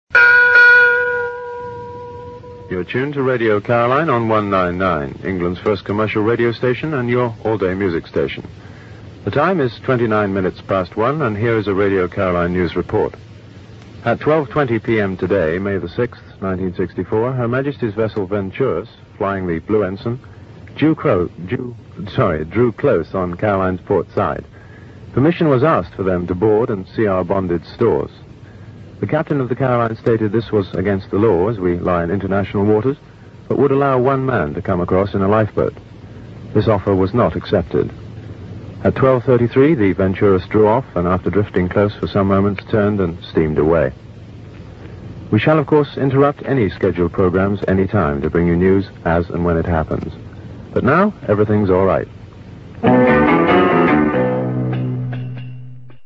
click to hear audio Simon Dee with a special “news report” on Radio Caroline, 6th May 1964 (duration 56 seconds)